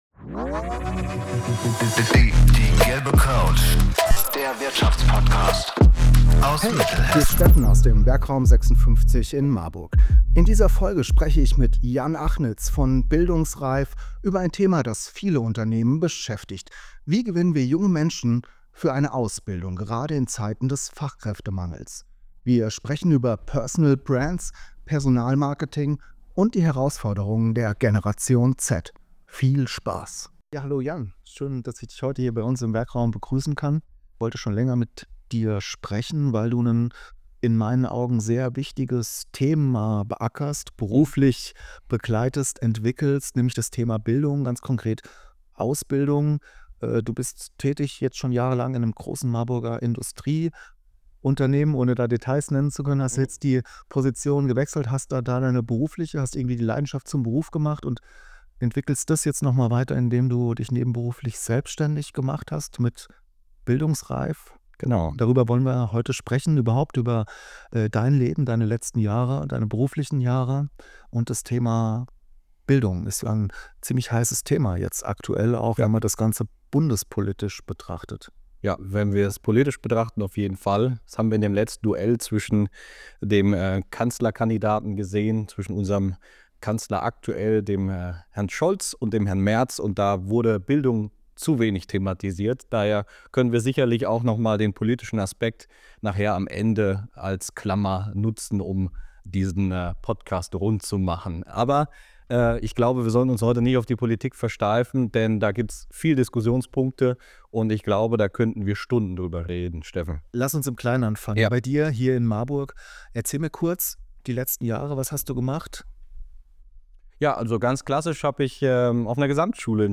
Intro & Begrüßung